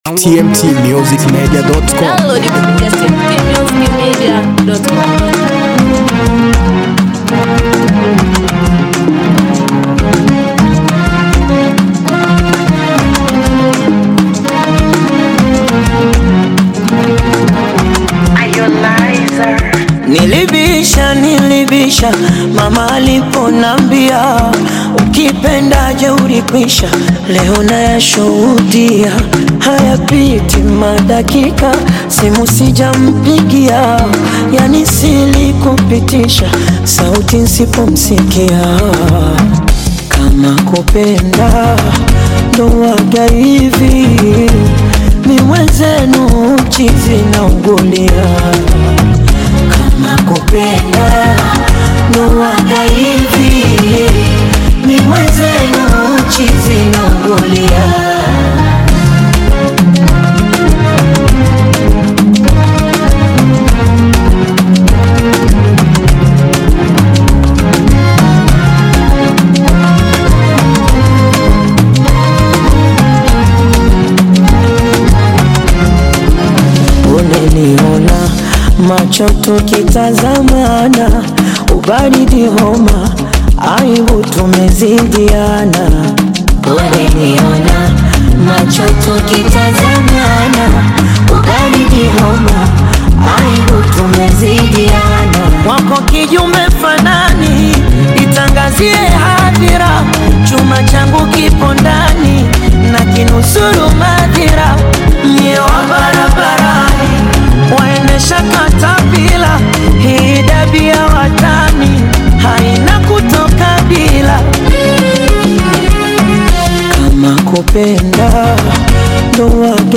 Afro beat
BONGO FLAVOUR